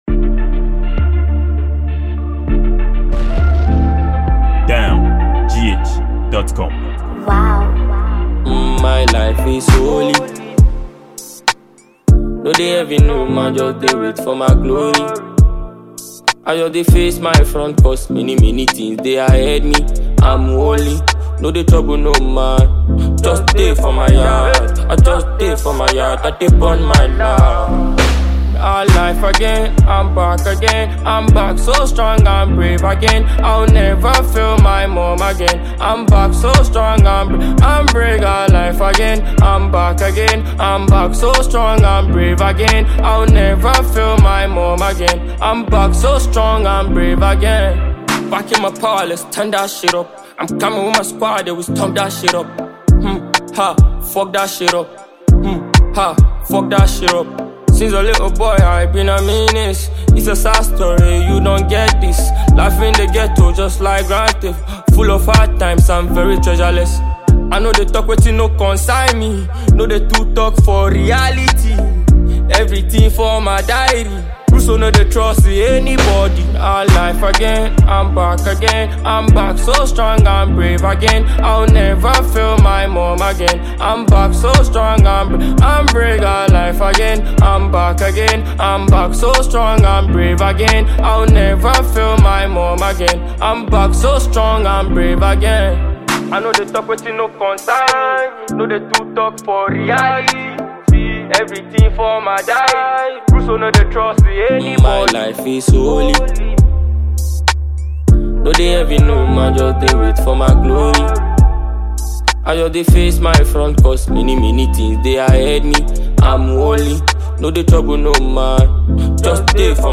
Ghanaian afrobeat dancehall musician